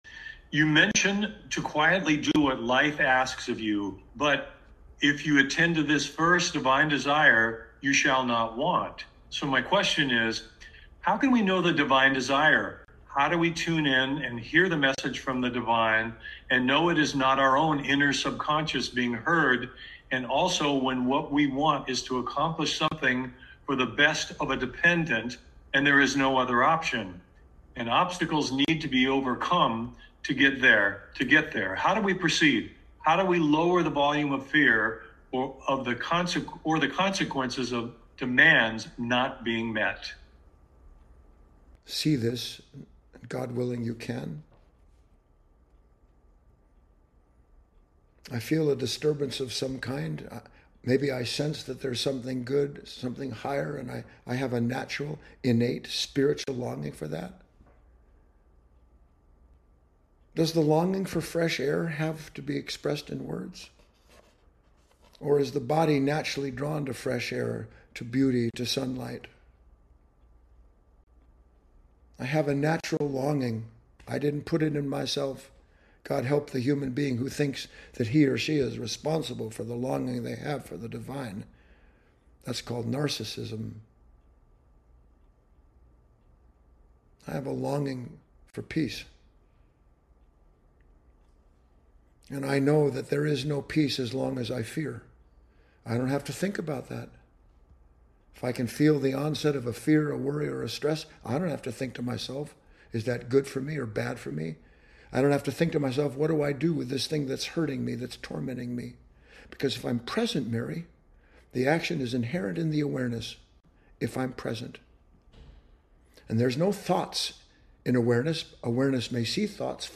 If you are engaged in an interior dialogue with yourself, you are not listening to Divine guidance. In this short talk